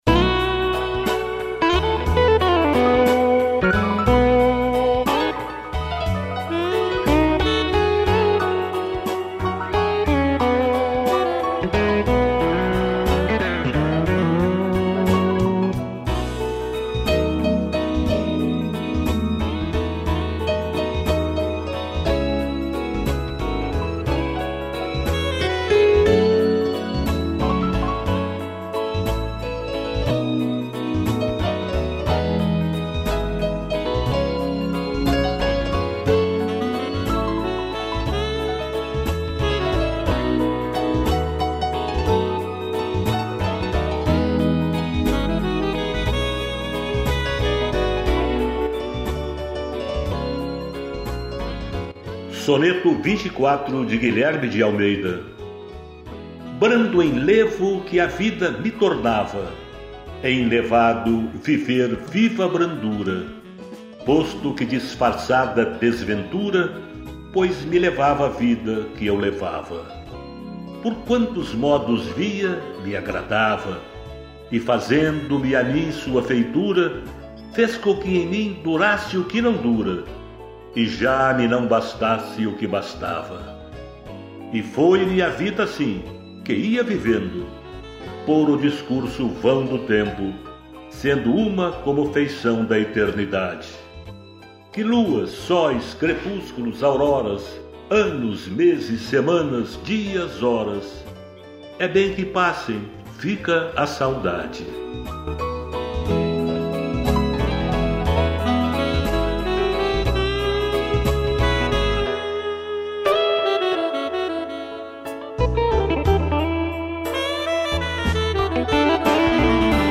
piano, sax e strings